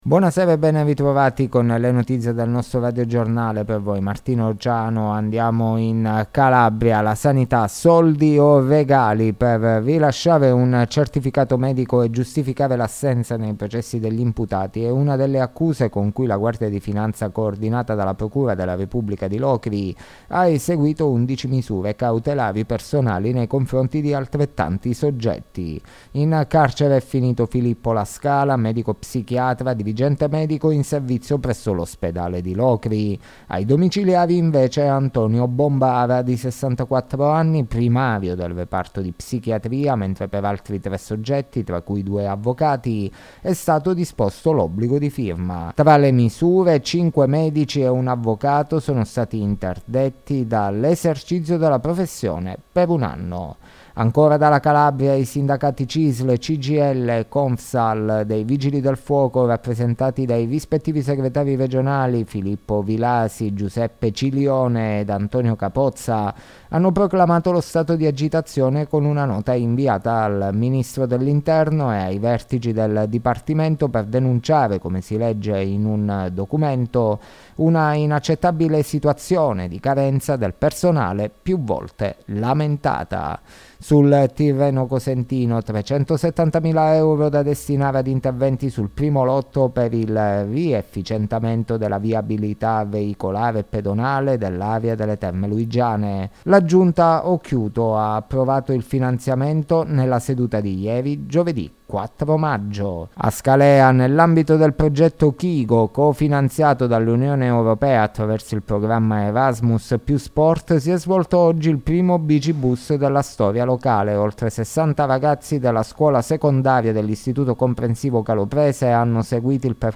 Le notizie della sera di Venerdì 05 Maggio 2023